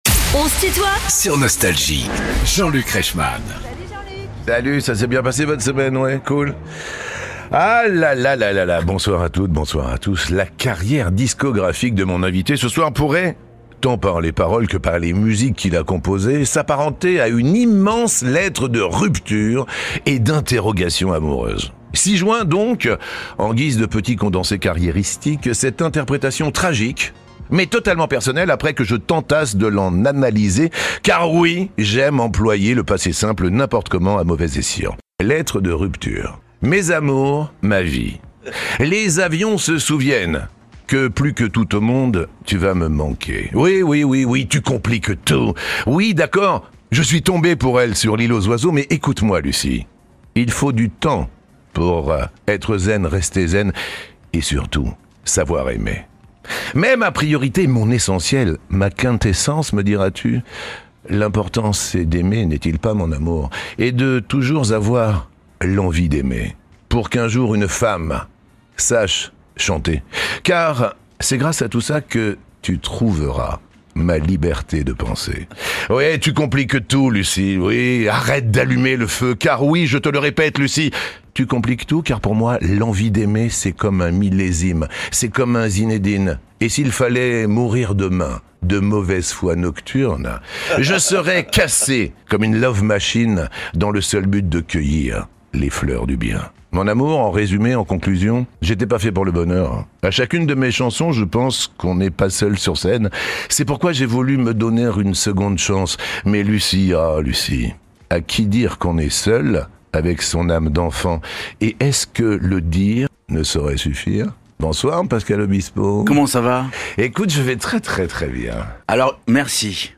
Pascal Obispo est l'invité de Jean-Luc Reichmann dans son émission "On se tutoie ?...", vendredi 21 février de 19h à 20h.